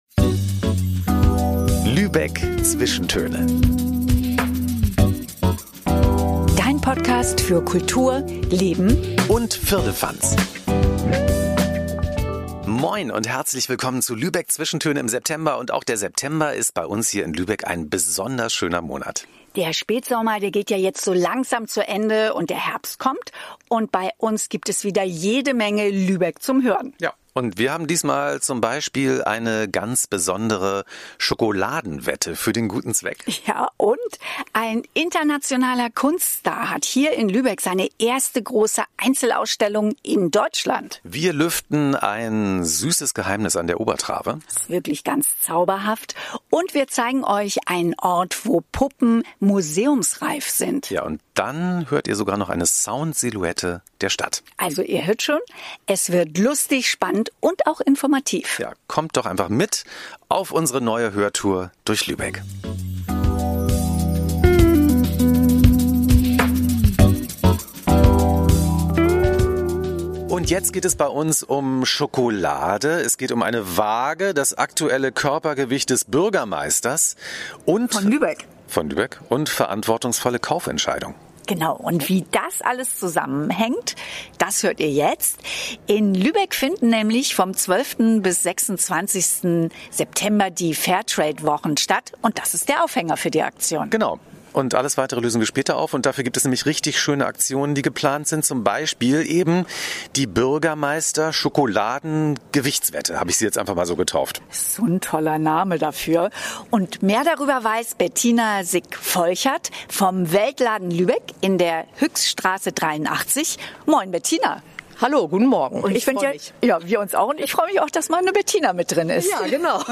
Und wir zeigen Euch dir einen magischen Ort zum Mitspielen, wo Puppen museumsreif sind. Außerdem hörst eine Sound-Silhouette der Stadt.